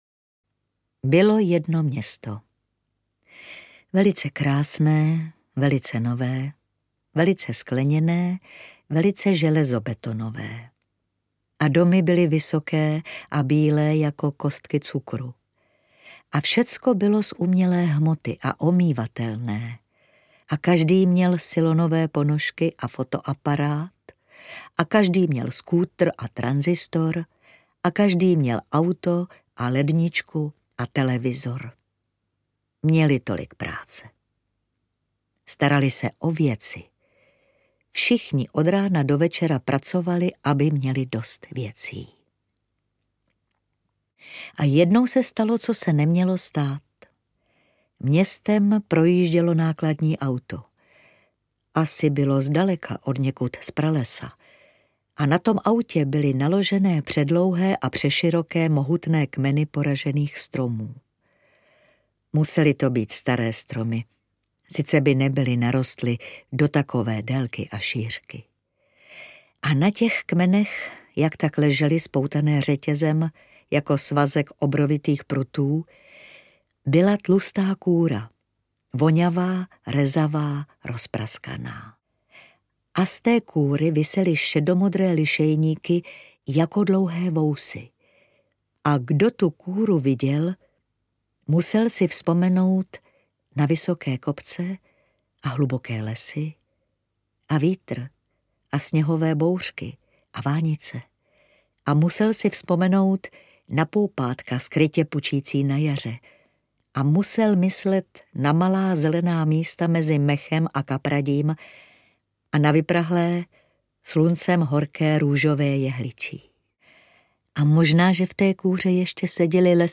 Auto z pralesa audiokniha
Ukázka z knihy
• InterpretMilena Steinmasslová